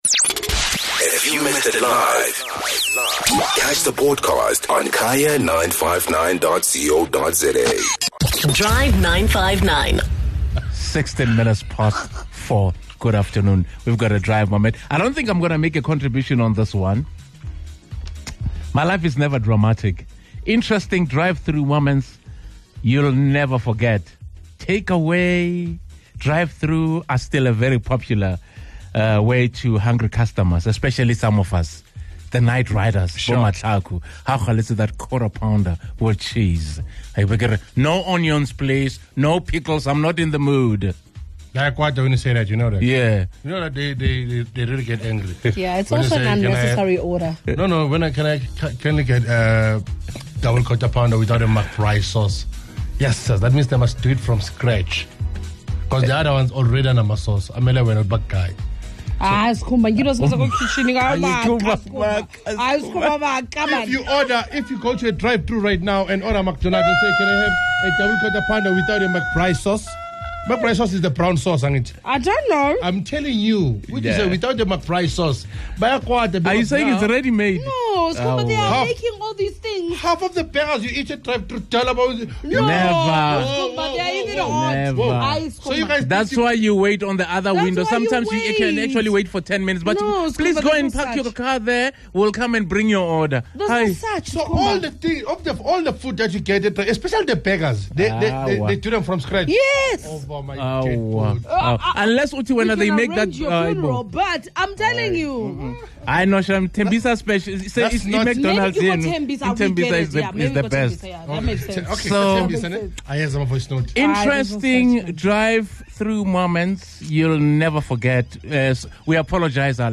While you were there, have you ever had anything interesting or unforgettable happen to you or around you? The Drive 959 listeners shared some stories that we never saw coming!